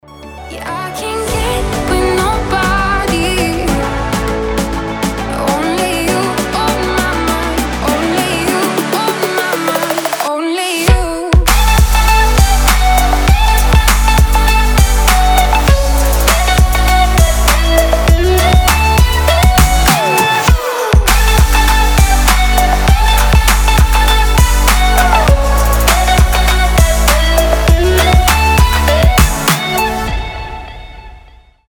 • Качество: 320, Stereo
громкие
EDM
future bass